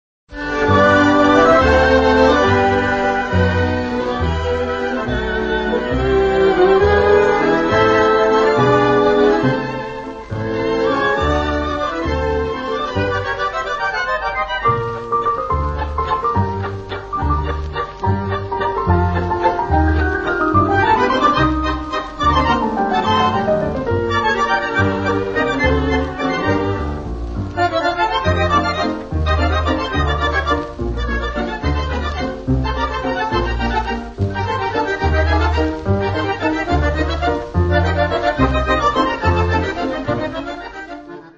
Vals